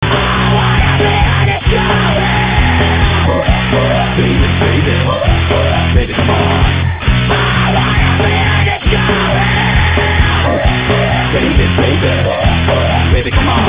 Zvonenia na mobil*(Ringtones):